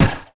bow_attack_shot.wav